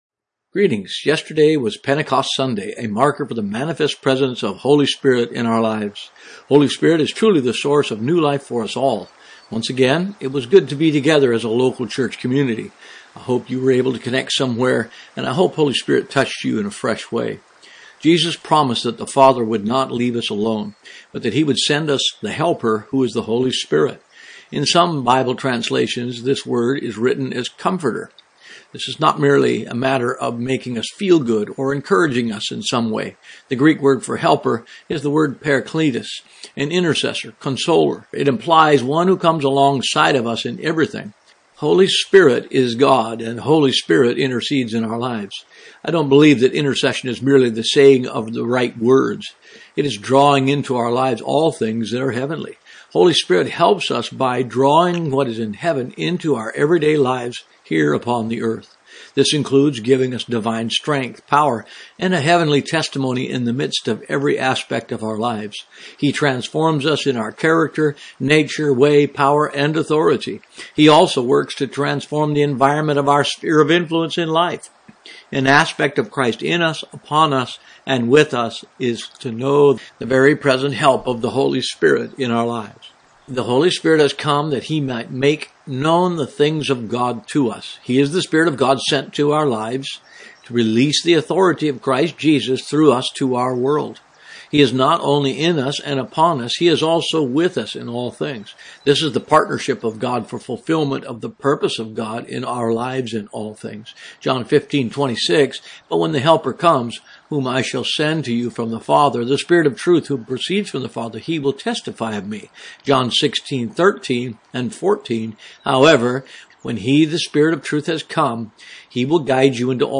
Blog In Audio: